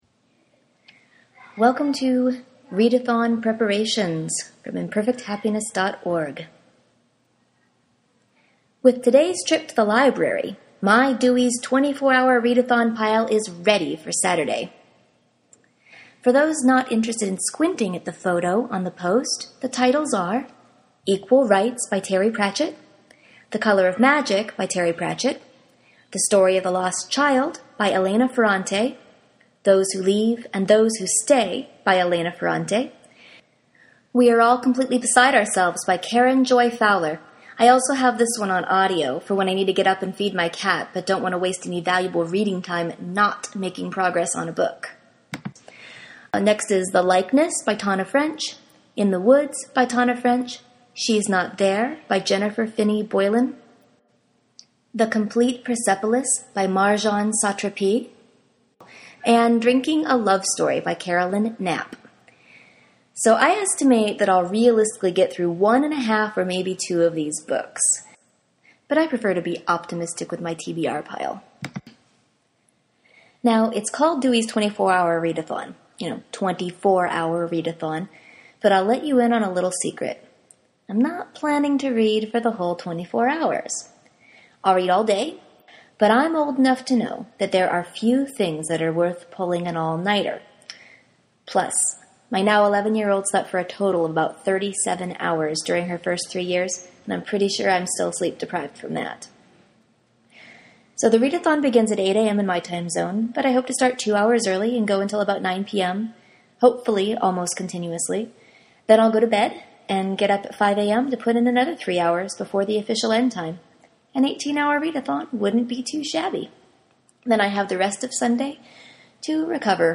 I’m playing around with some podcasting/audio stuff, and so I made an audio recording of today’s post.